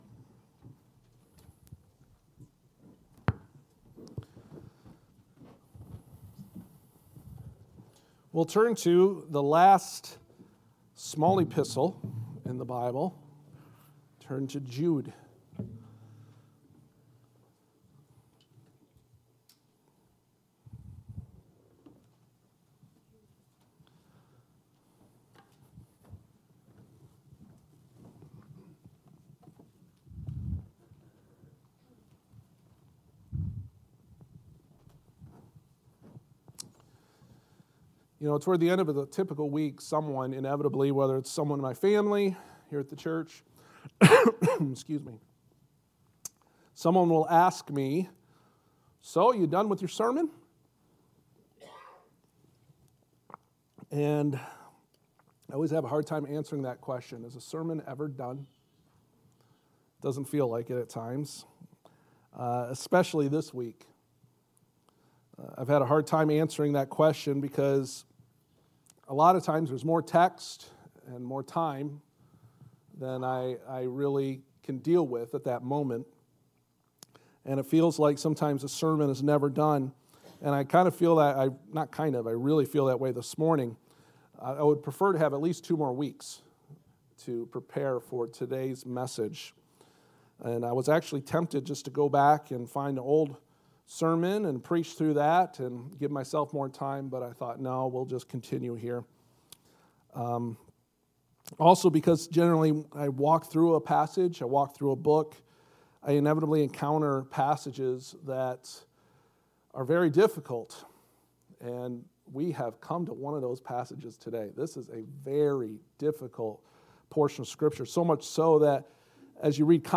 Jude 8-10 Service Type: Sunday Morning « Three Pictures of False Teachers Biography of False Teachers